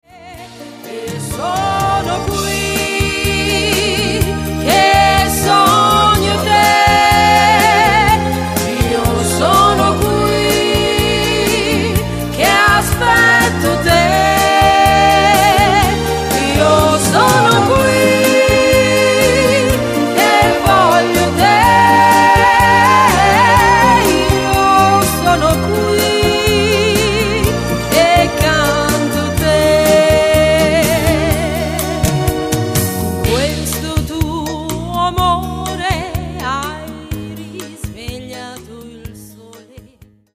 MODERATO  (3.10)